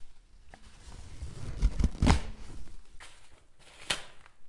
厕纸撕裂
描述：一张卫生纸被扯掉在浴室里的卷。 使用Tascam DR4048kHz 24bit录制